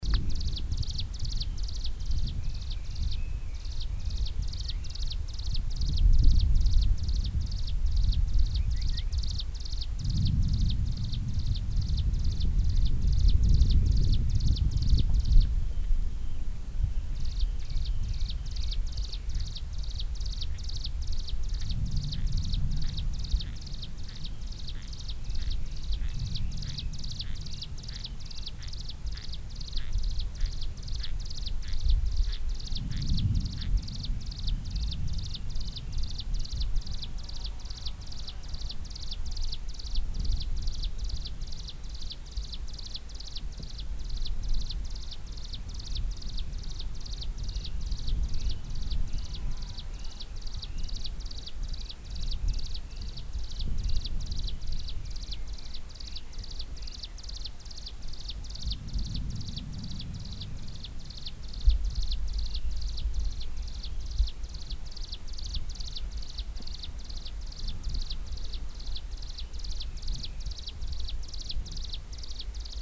Prinia crinigère ( Prinia crinigera ) ssp parumstriata
Chant enregistré le 09 mai 2012, en Chine, province du Fujian, réserve de Dai Yun Shan.